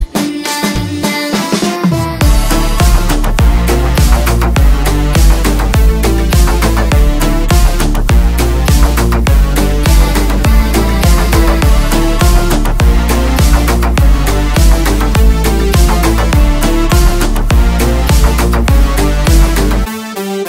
• Качество: 320, Stereo
громкие
мощные
Electronic
EDM
Стиль: house